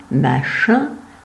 Marsens (Freiburger Patois
Frp-greverin-Machin.ogg.mp3